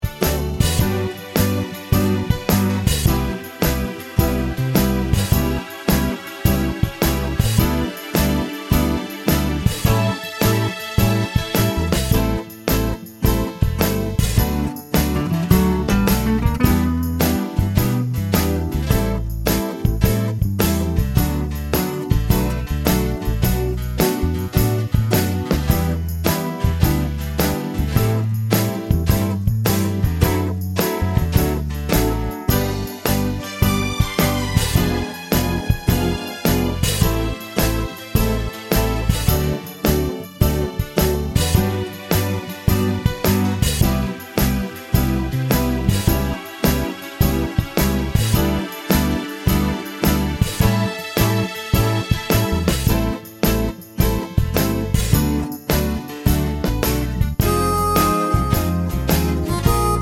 no harmonica Pop (1970s) 3:01 Buy £1.50